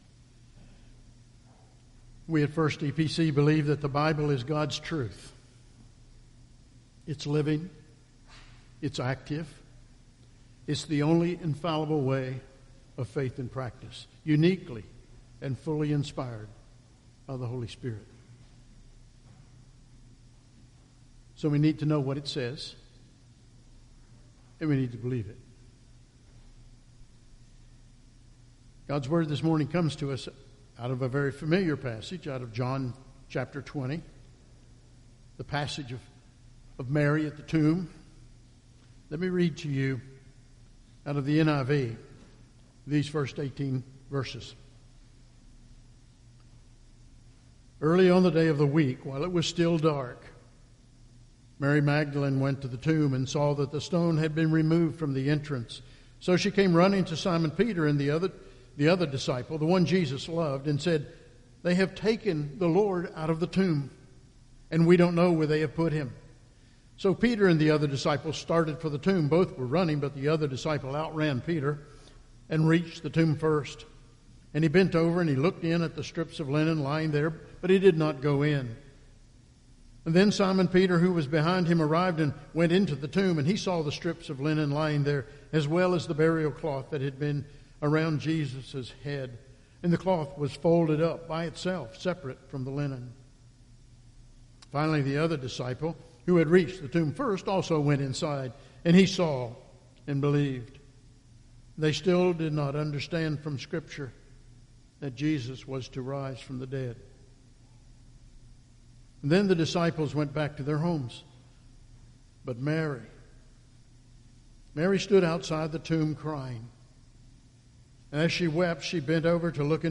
Sermon audio from the pulpit of First Evangelical Presbyterian Church Roanoke